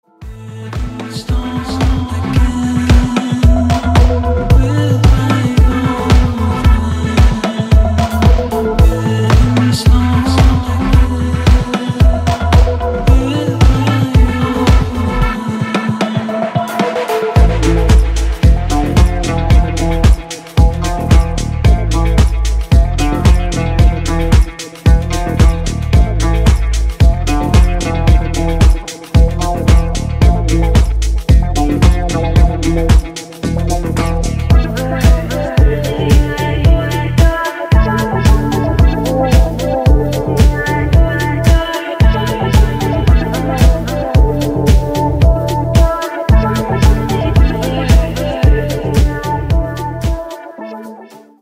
• Качество: 320, Stereo
гитара
deep house
атмосферные
красивый мужской голос
Electronic
струнные